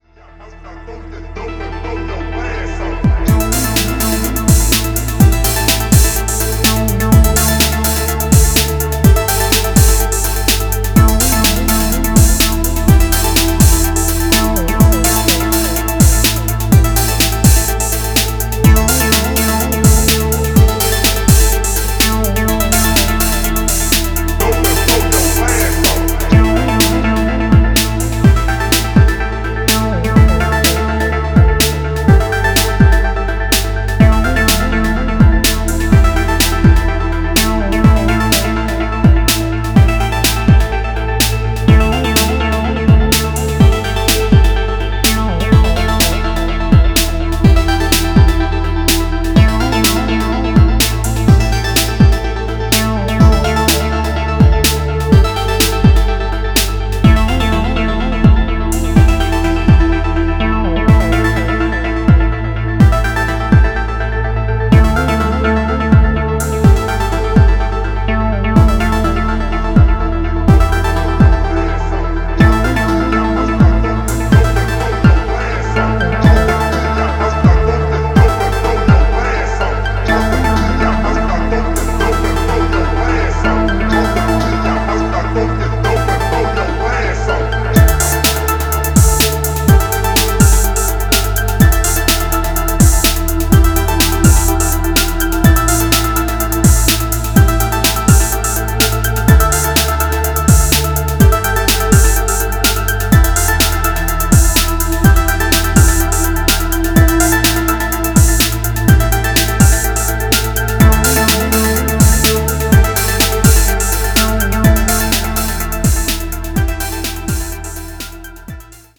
Electro Techno Trance